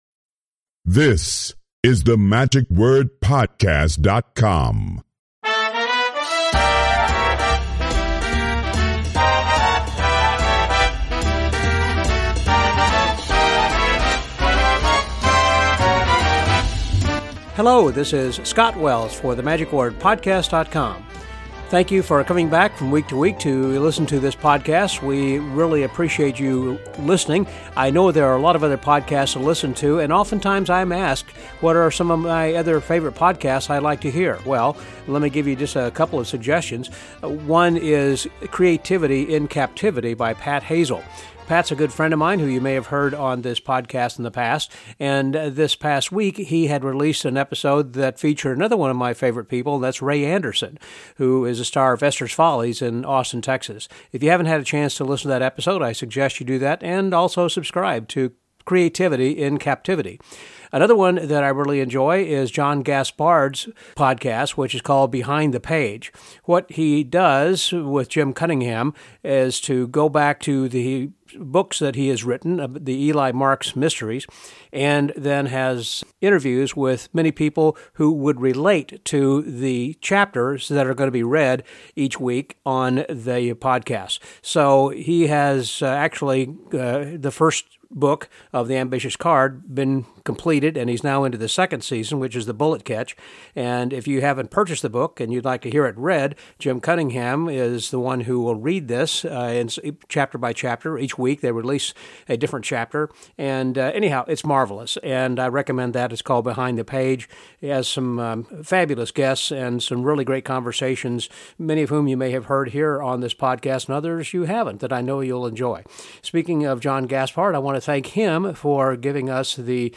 While at the 2022 Magifest in Columbus, Ohio, we sat down with one of the presenters